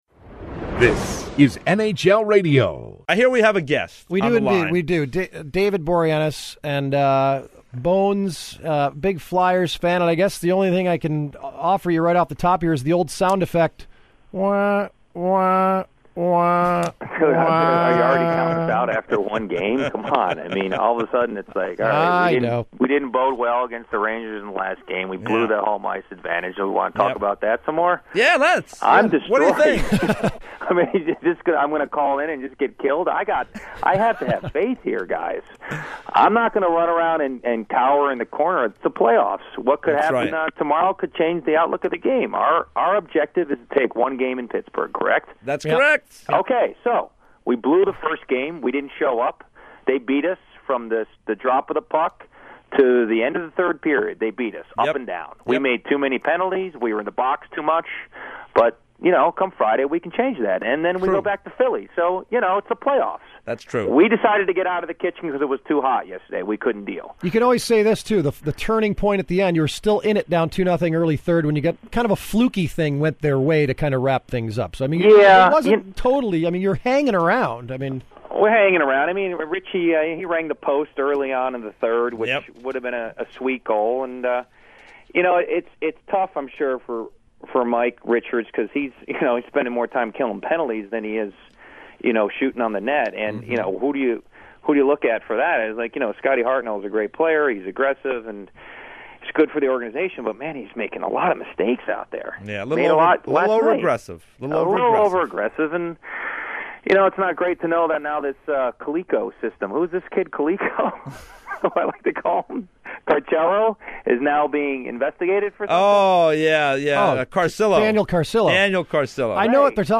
No estúdio da Rádio NHL nada mais nada menos que David Boreanaz contando como foi "agressiva" a cena de amor.... e tal.
" David Boreanaz em entrevista a rádio NHL.